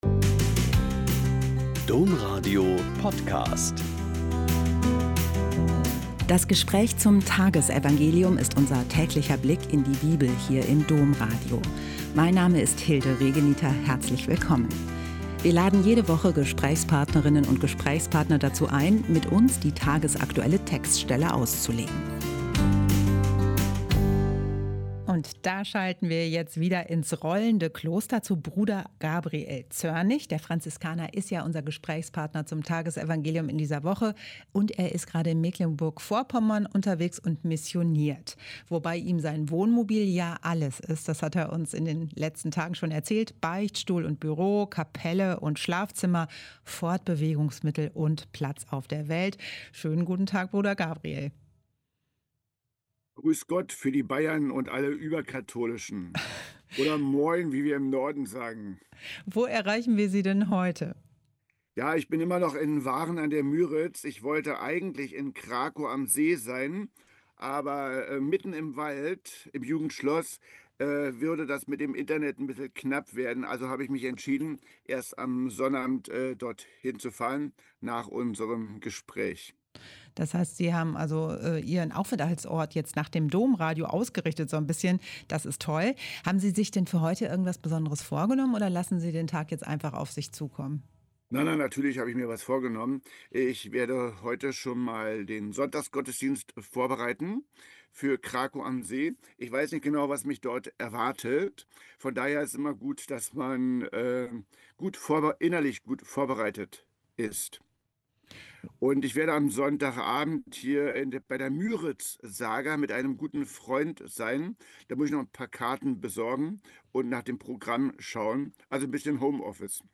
Mt 16,13-23 - Gespräch